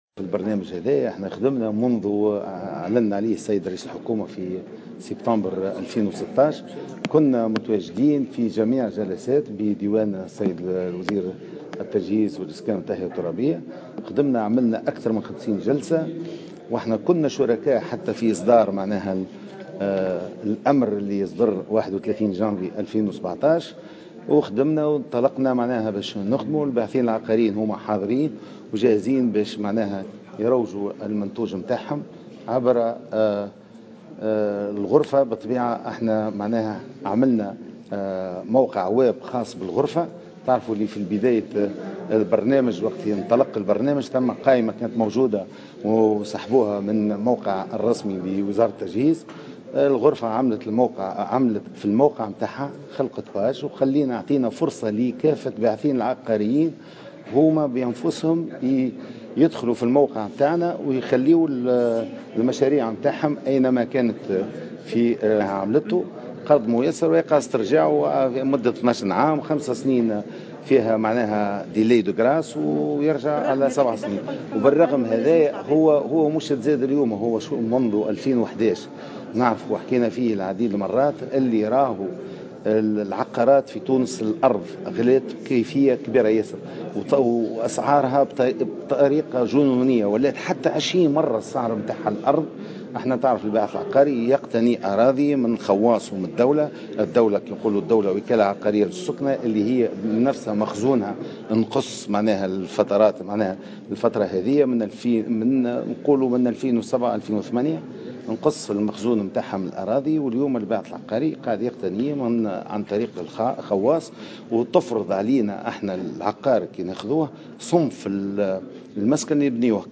تصريح لمراسل الجوهرة "اف ام" على هامش ندوة صحفية